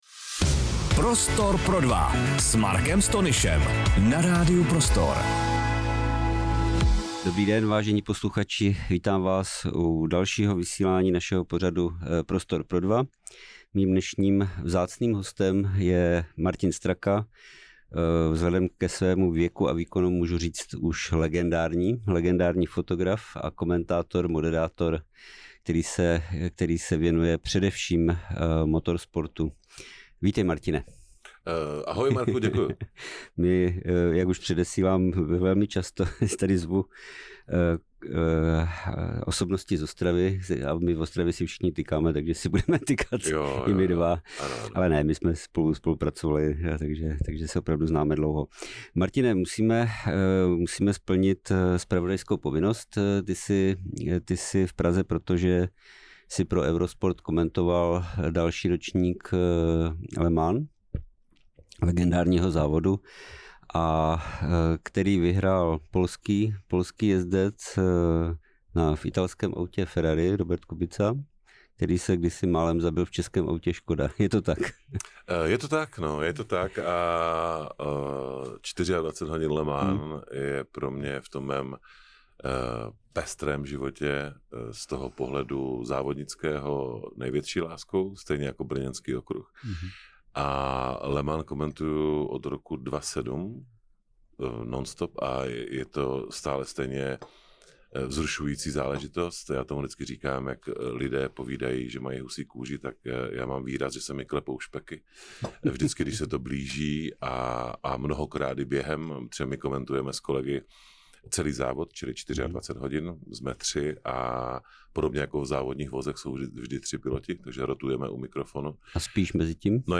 rozhovoru